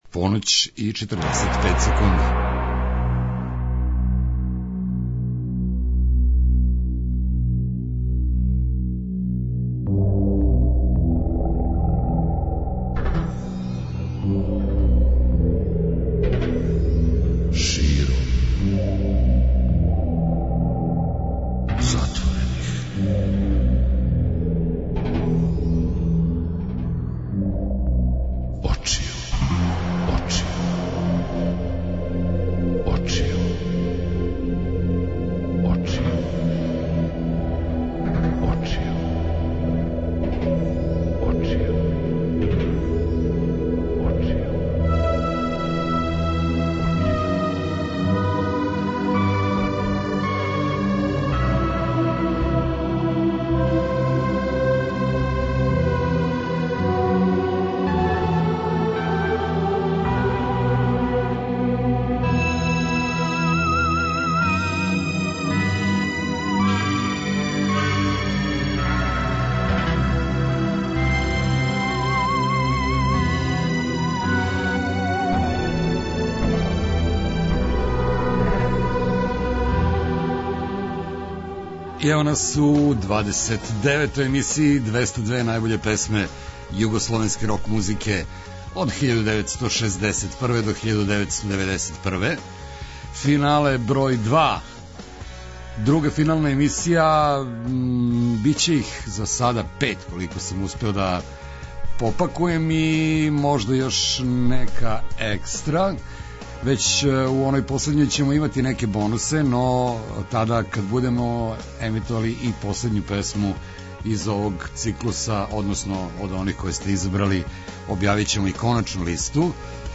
Током октобра и (можда) почетком новембра, сваке седмице, у ноћи између среде и четвртка, слушаћемо управо те, одабране, песме и причати о њима. Друга емисија финала овог циклуса посвећена је издањима објављеним у периоду 1978-1982.
преузми : 57.09 MB Широм затворених очију Autor: Београд 202 Ноћни програм Београда 202 [ детаљније ] Све епизоде серијала Београд 202 Говор и музика Састанак наше радијске заједнице We care about disco!!!